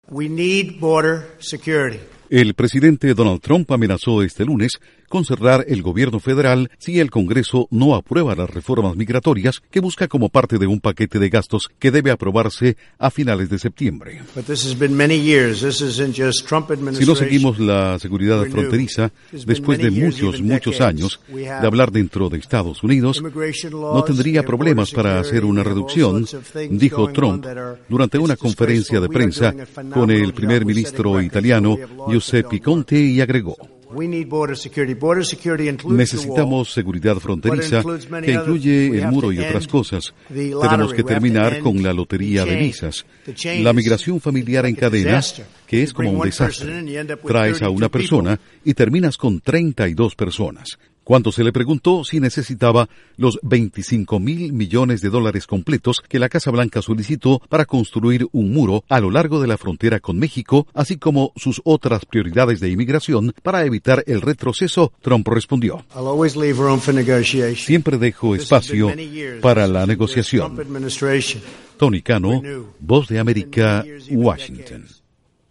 Trump amenaza nuevamente con el cerrar el gobierno de EE.UU. si no hay reformas migratorias y seguridad fronteriza. Informa desde la Voz de América en Washington